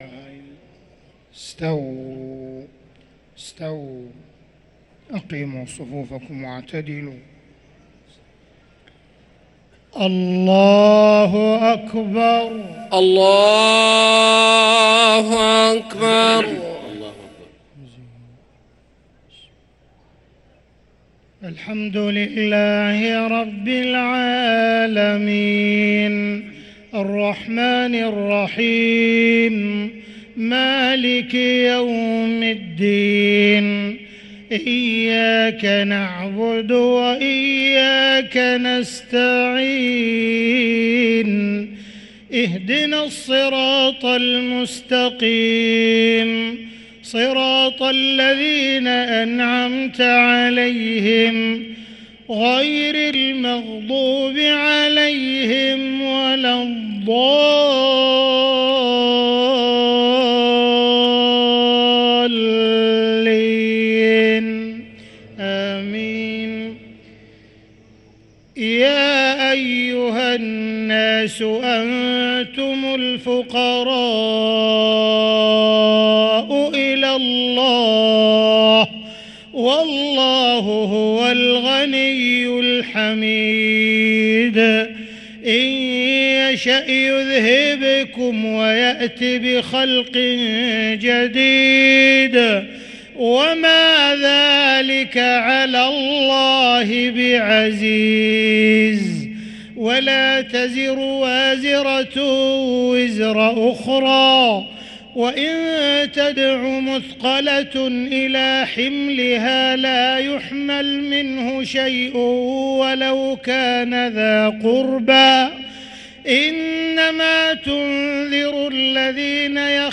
صلاة المغرب للقارئ عبدالرحمن السديس 23 رمضان 1444 هـ
تِلَاوَات الْحَرَمَيْن .